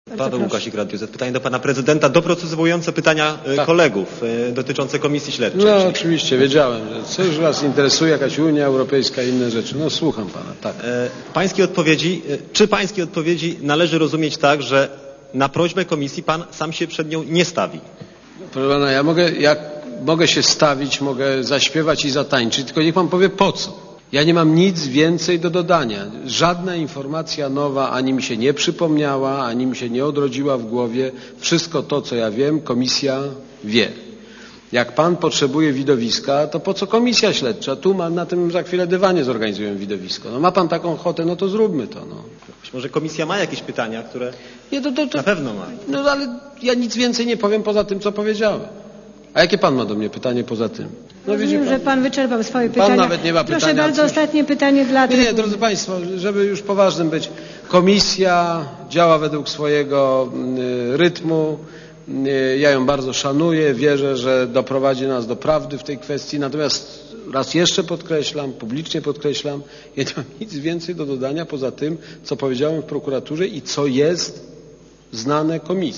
mówi Aleksander Kwaśniewski
"Nic więcej sobie nie przypomnę. Jeśli chcecie państwo widowiska, to mogę przed Komisją zatańczyć i zaśpiewać, tylko po co?" - powiedział Aleksander Kwaśniewski dziennikarzom po posiedzeniu Rady Gabinetowej.